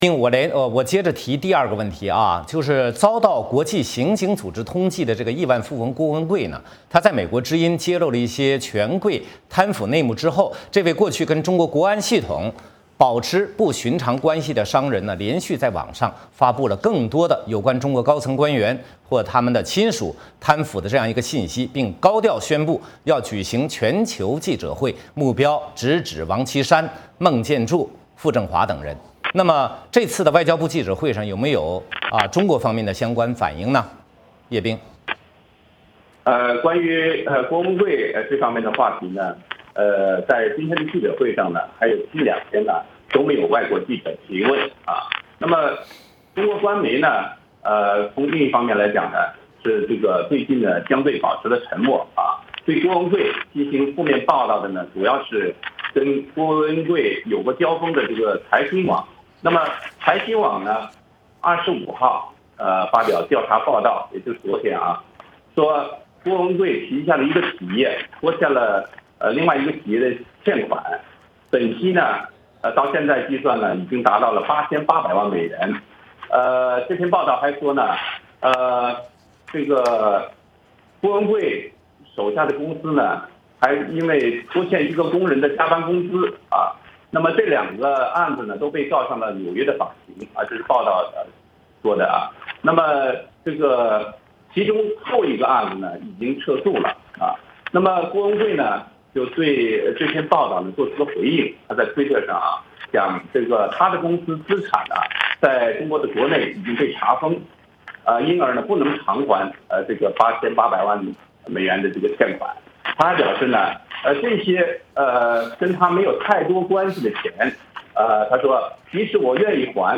华盛顿 —